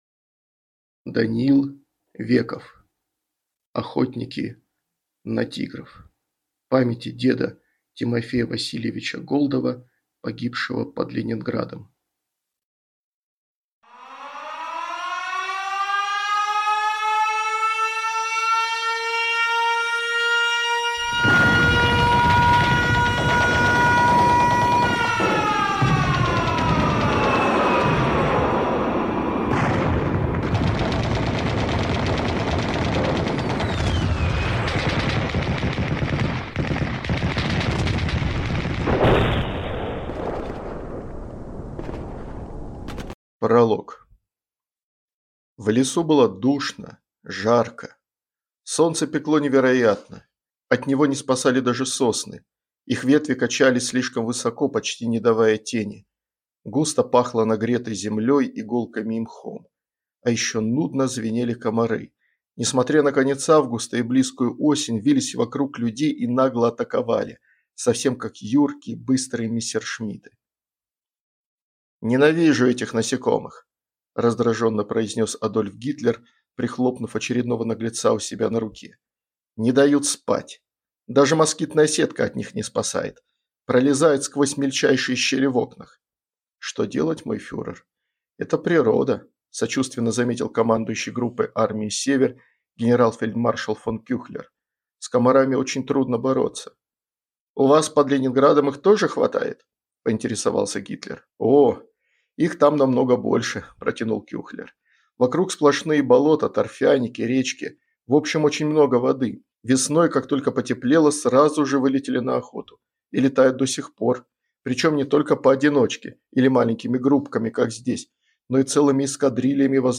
Аудиокнига Охотники на «Тигров» | Библиотека аудиокниг
Прослушать и бесплатно скачать фрагмент аудиокниги